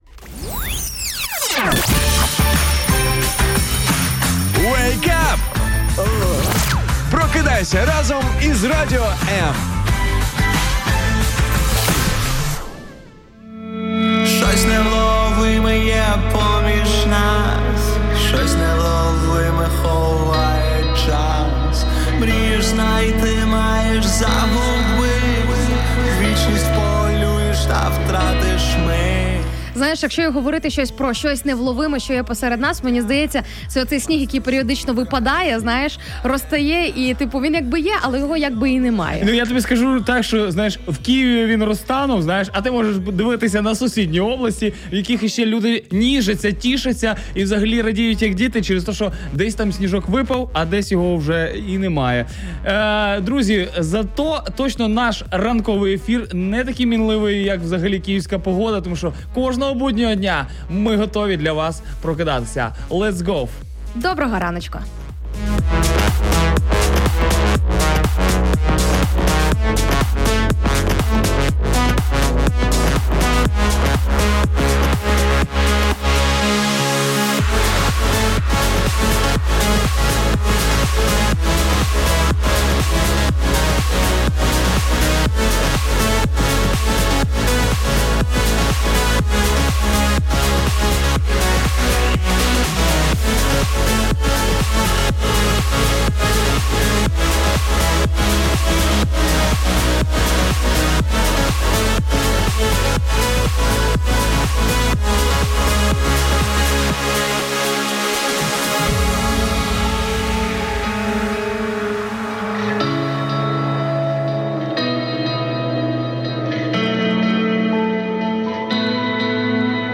Про це міркували цього ранку За які речі вам соромно? 09/12/2021 Соромно за свої слова або що таке іспанський сором. Про життєві ситуації спілкувалися з cлухачами ранкові ведучі